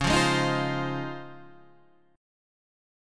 C# Major 9th